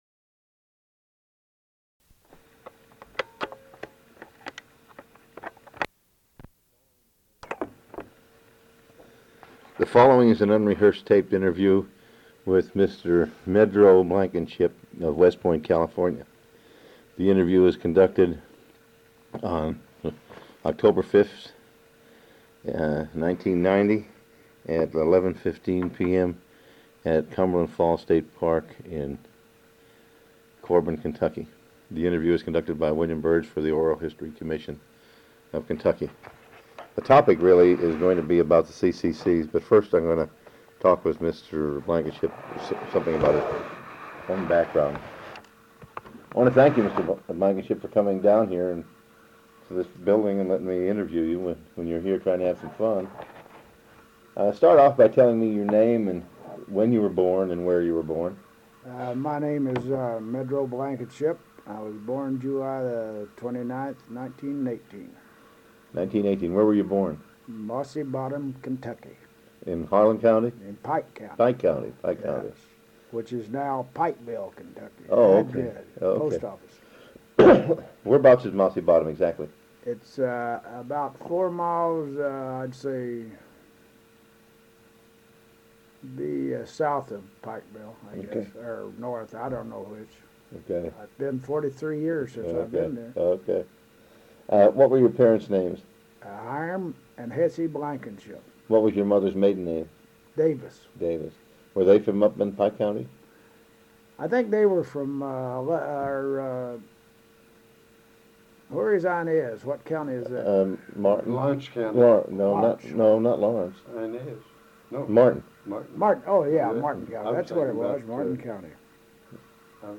Kentucky Historical Society